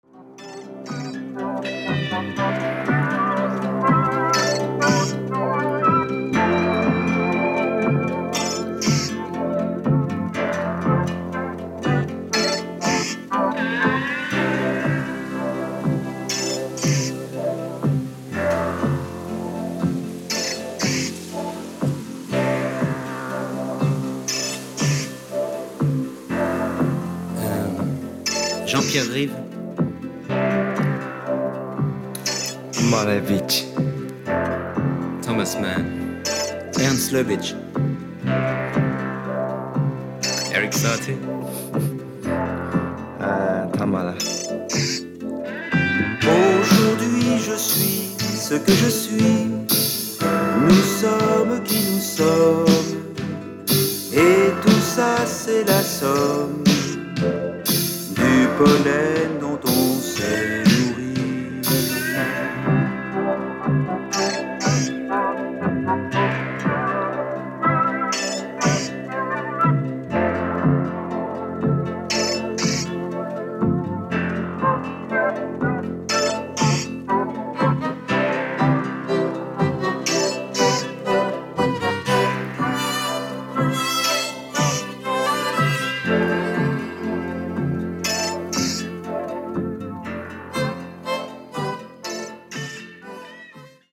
Backing Vocals